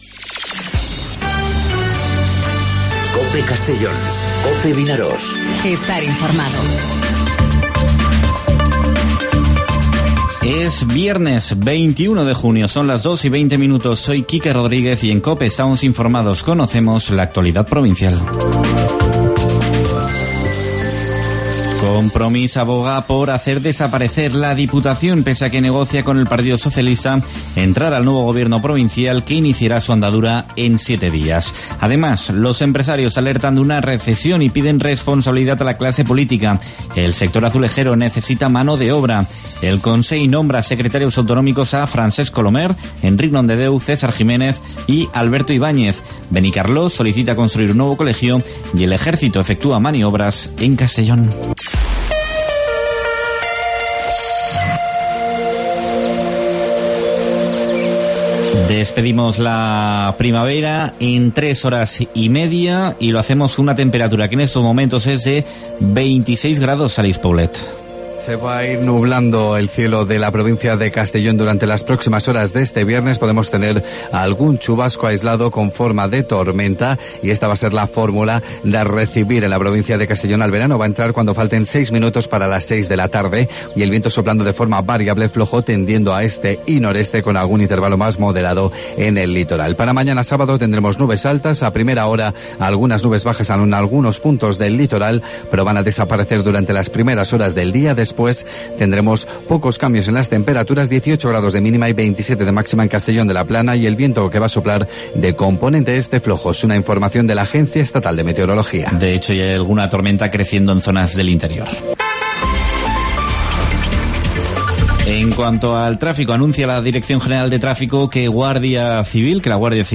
Informativo 'Mediodía COPE' en Castellón (21/06/2019)